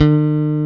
EPM E-BASS.3.wav